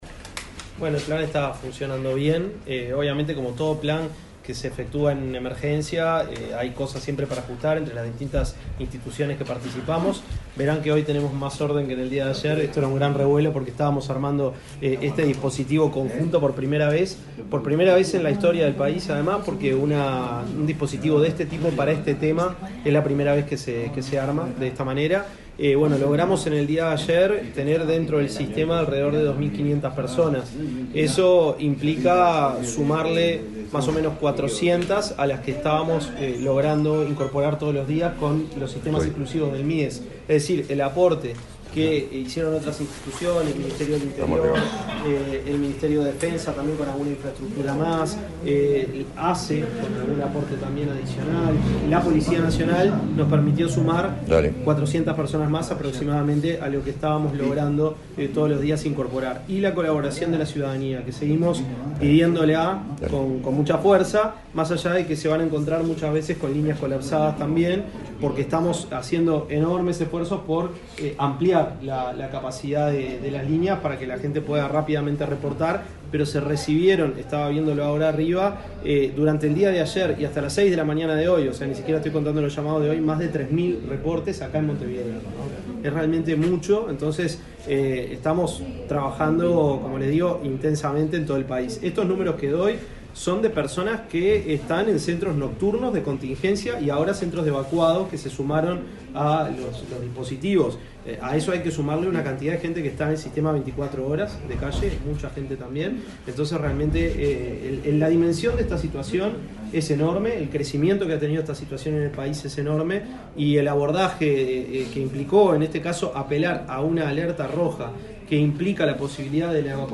Declaraciones del ministro de Desarrollo Social, Gonzalo Civila
El ministro de Desarrollo Social, Gonzalo Civila, realizó declaraciones sobre el plan para las personas en situación de calle, debido a la ola de frío